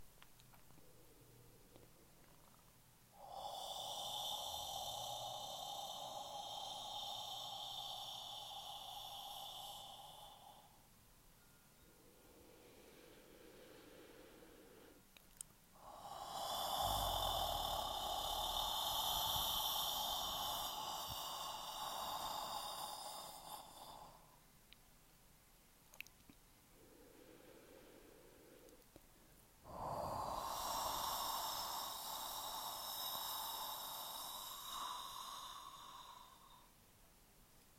Second Sound: HO
Similarity: like the end of a snore